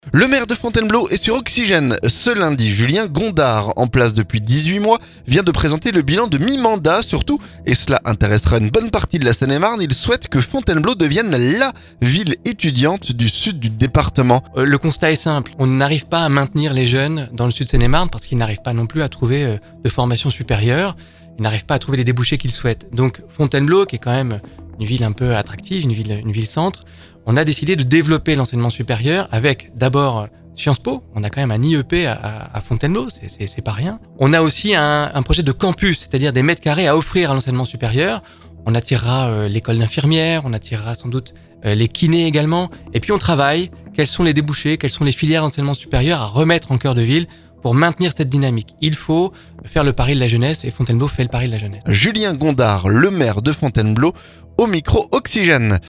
Le maire de Fontainebleau est sur Oxygène ce lundi. Julien Gondard, en place depuis près de 18 mois, vient de présenter le bilan de mi-mandat.